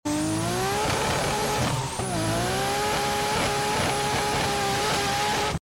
2jz Gte Motor Sound Insane Sound Effects Free Download